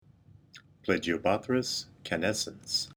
Pronunciation/Pronunciación:
Pla-gi-o-bó-thrys ca-nés-cens